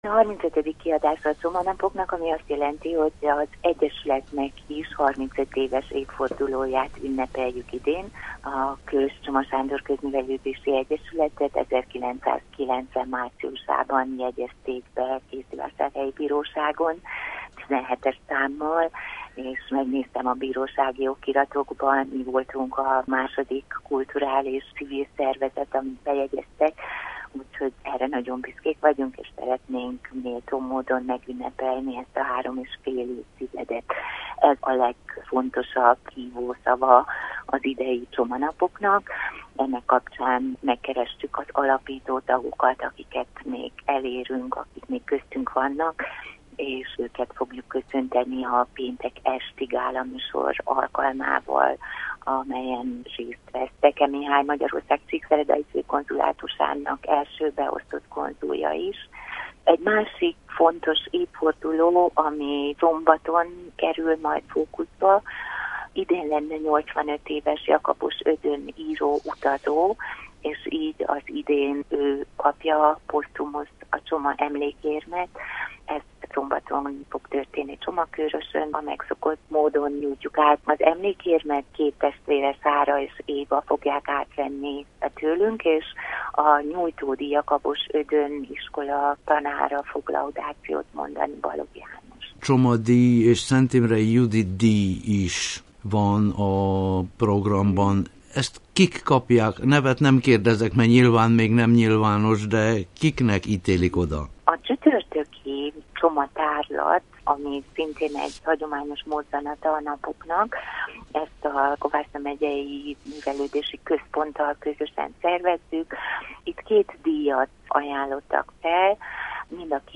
Bukaresti Rádió: Interjú a Hétköznapok műsorban